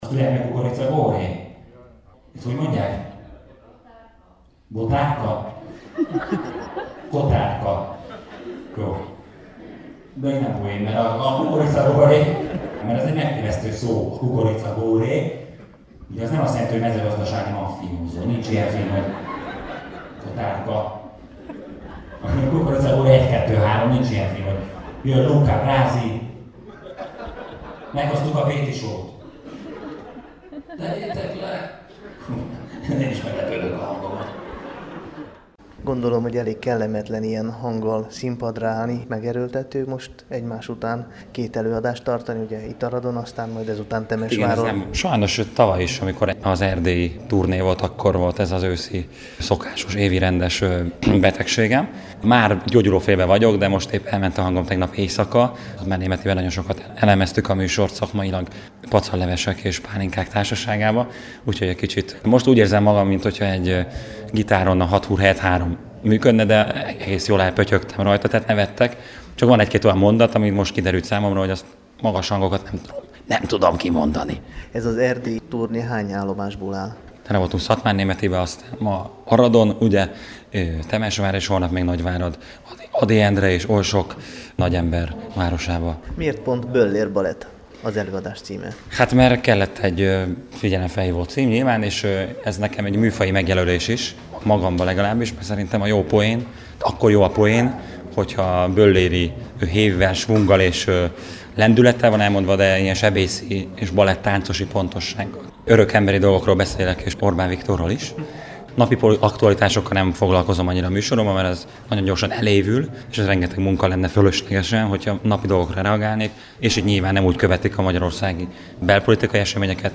A Showder Klubból is ismert három humorista – Bödőcs Tibor, Mogács Dániel és Tóth Eduárd – úgymond bemelegítés nélkül (Bödőcs ráadásul egy eléggé elhatalmasodó torokgyulladással bajlódva) szórakoztatta a nézőket, akik bizonyára rekeszizomlázzal távoztak a másfél óra végén.